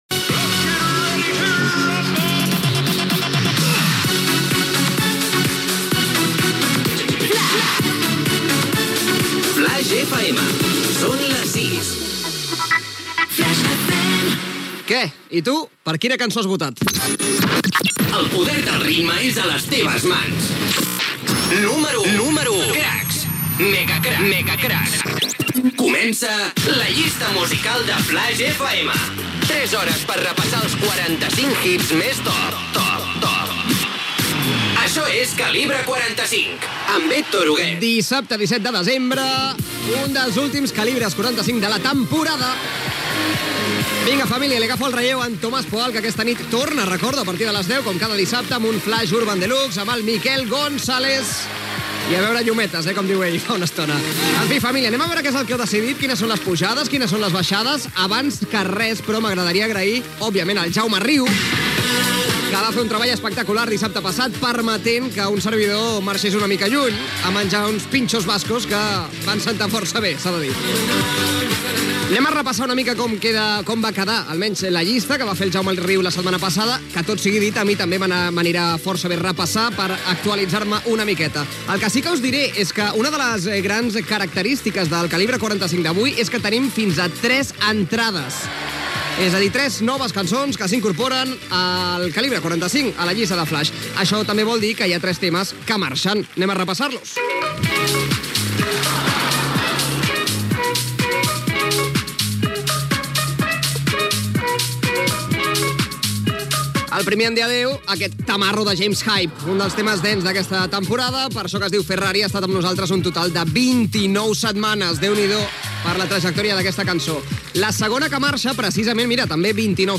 Indicatiu de l'emissora, hora, indicatiu del programa, data, salutació inicial, repàs als temes que surten de la llista d'èxits de l'emissora, el "megacrak de la setmana", podi d'èxits.
Musical